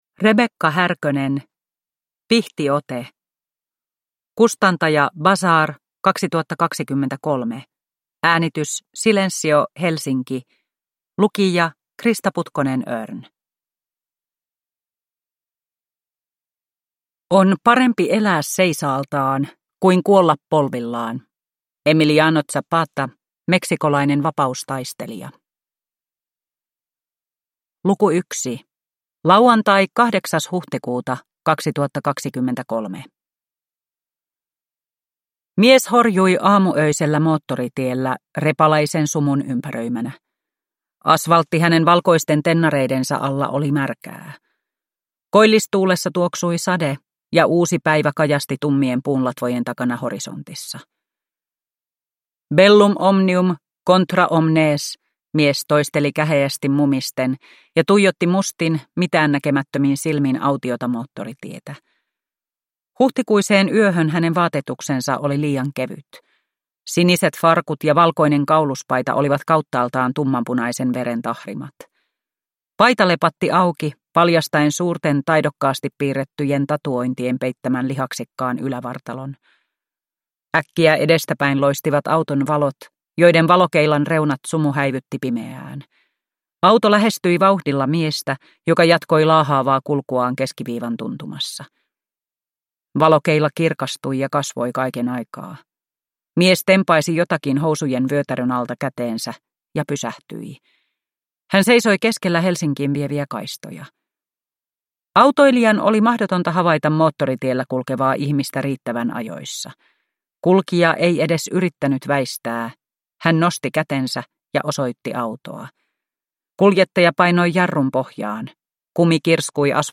Pihtiote – Ljudbok – Laddas ner
Uppläsare: